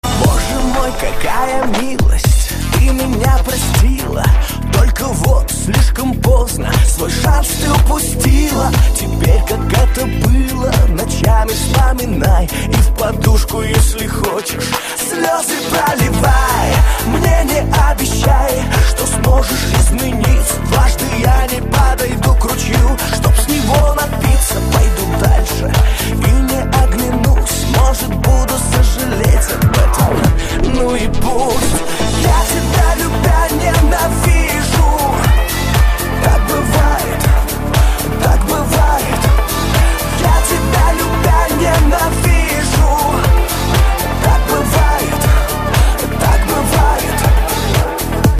• Качество: 128, Stereo
мужской вокал